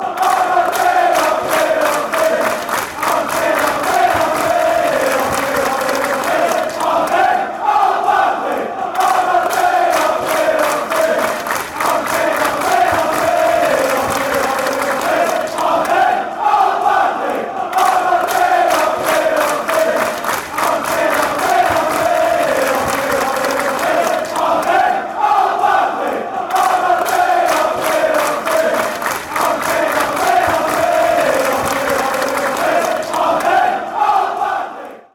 soccer chant